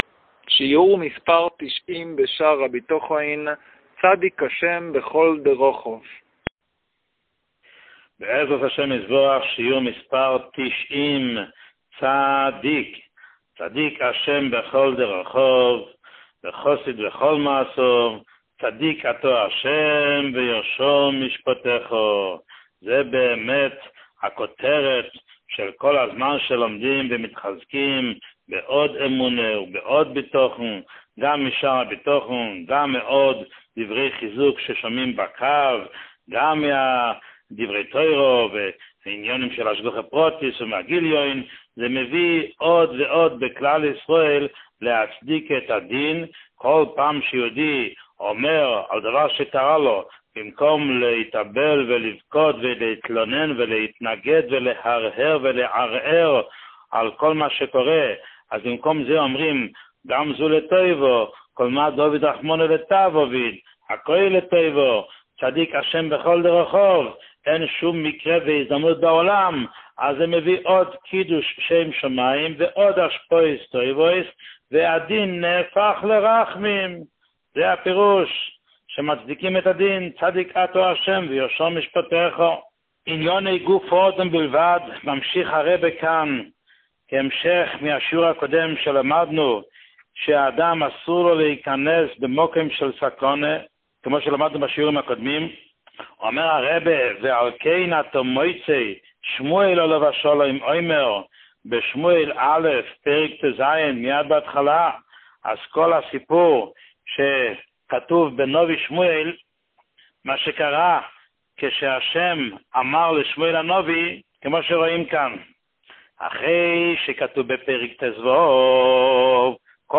שיעור 90